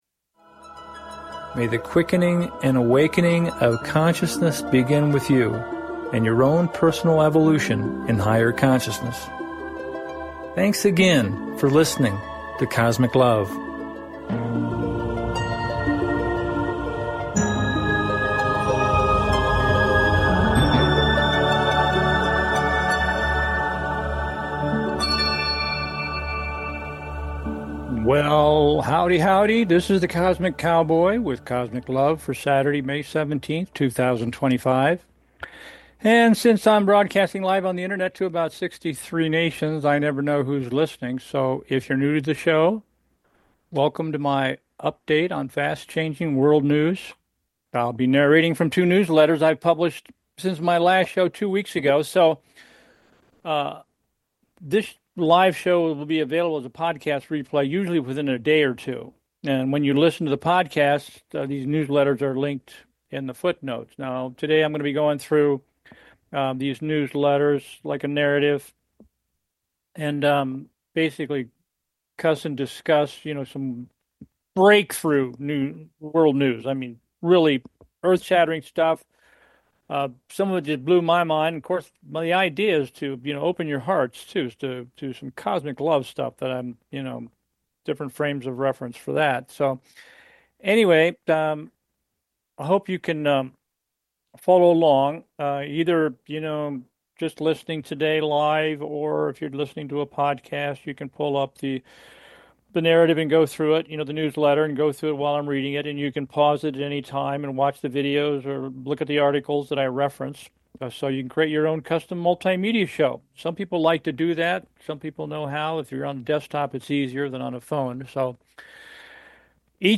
Narrating from two recent newsletters: